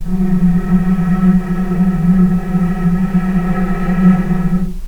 vc-F#3-pp.AIF